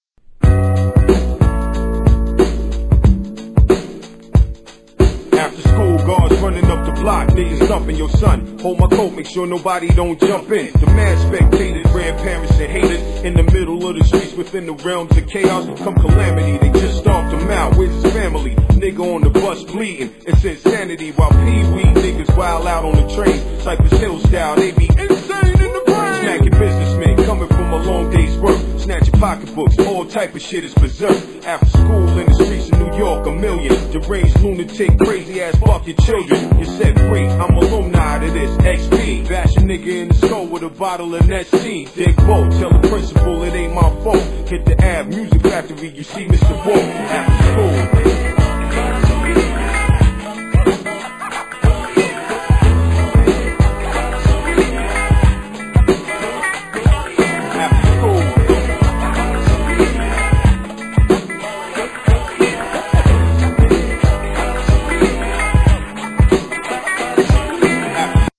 1. Hood version ==>